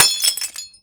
slapstickGlassBreak.ogg